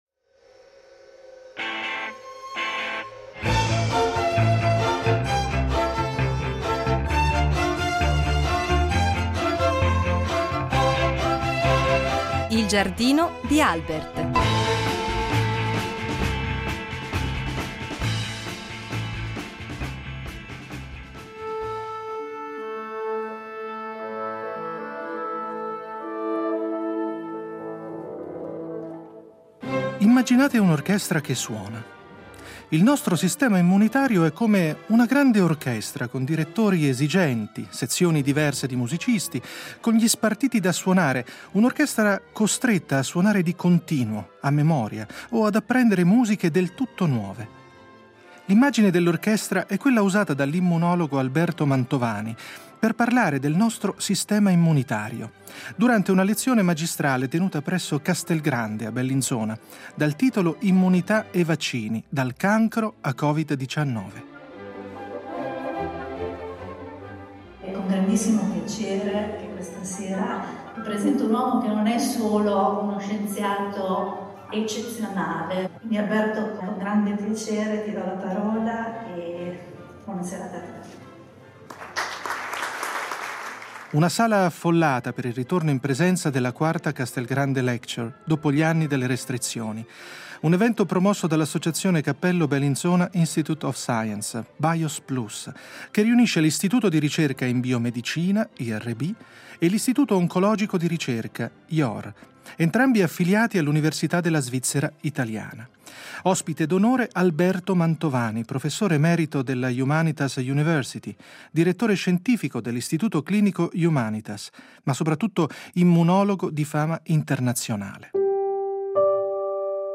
Vaccini e cancro: la parola all’immunologo Alberto Mantovani . Il nostro sistema immunitario è come una grande orchestra, con direttori, sezioni diverse di musicisti, e tanti spartiti da suonare…